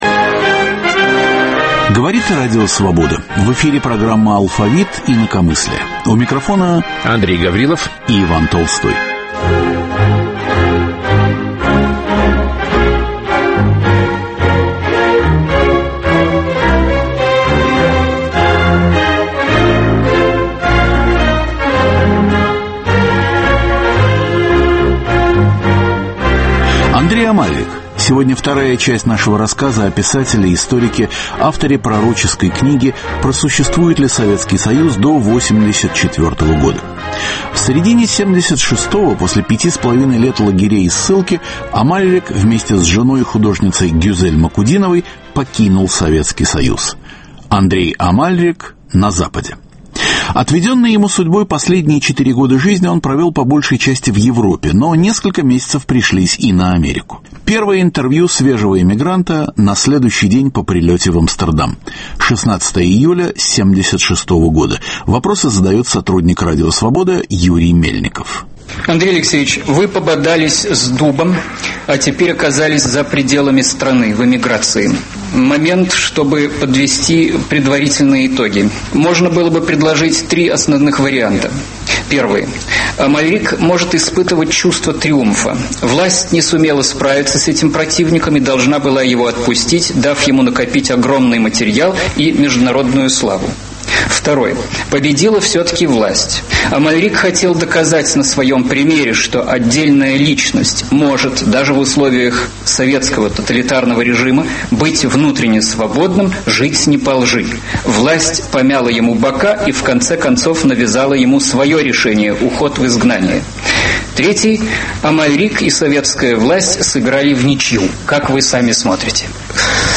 В конце 1980 года, пробираясь по ночной горной дороге в Испанию, попал в автомобильную катастрофу и погиб. В программе впервые прозвучит интервью со свидетелем катастрофы, находившимся с Амальриком в одной машине.